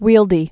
(wēldē)